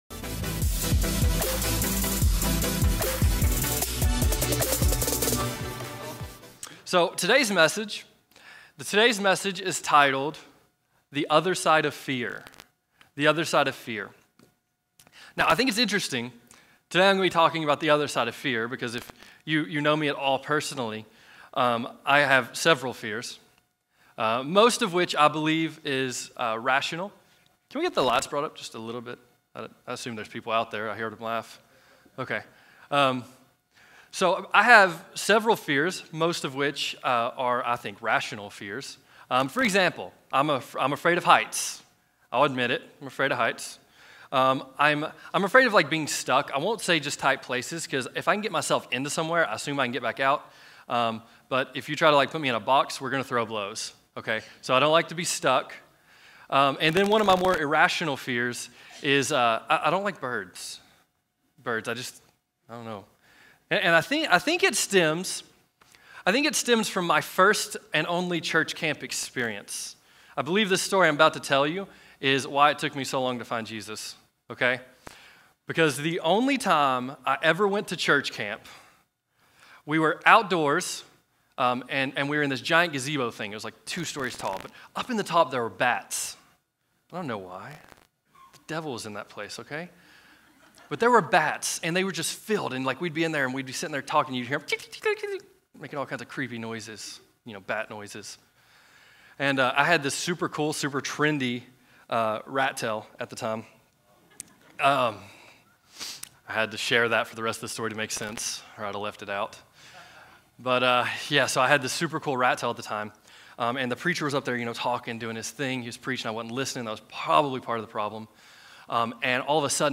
Listen to the next part of our sermon series “Spirit of the Church”